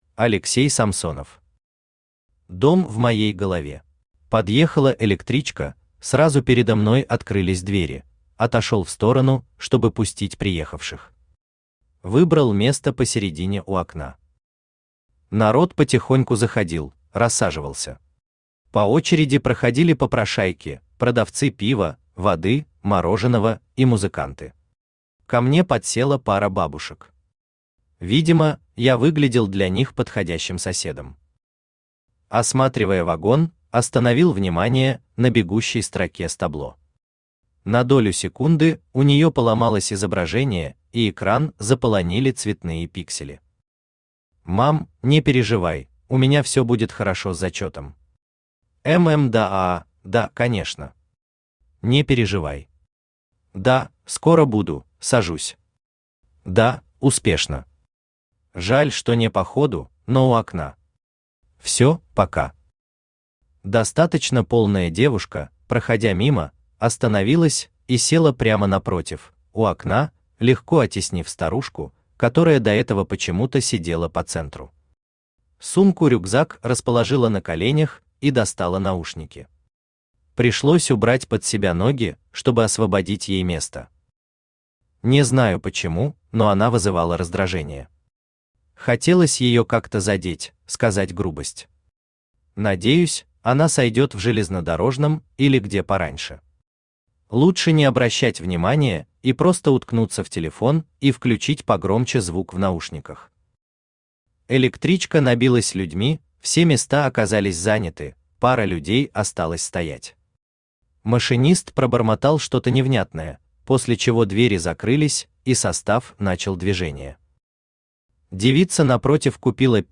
Aудиокнига Дом в моей голове Автор Алексей Самсонов Читает аудиокнигу Авточтец ЛитРес.